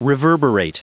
Prononciation du mot reverberate en anglais (fichier audio)
Prononciation du mot : reverberate